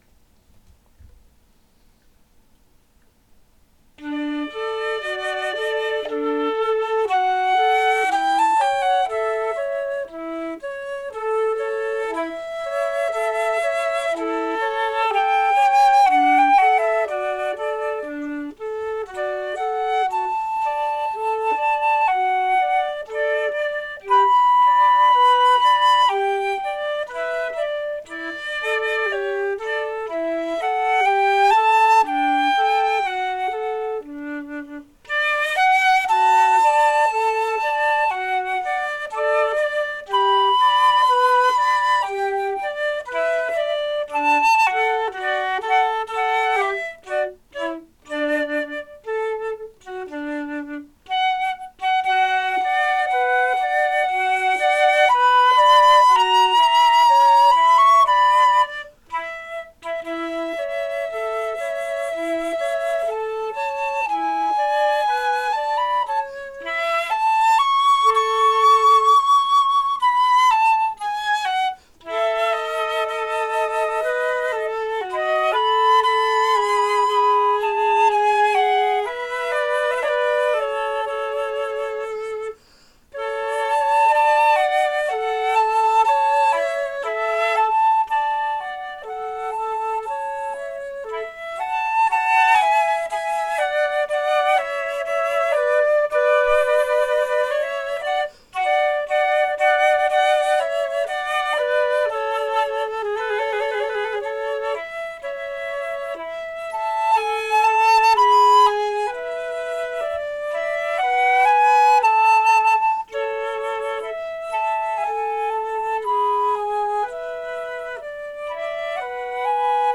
Carl Stamitz wrote a series of duets for violin that have been arranged for two flutes.
flute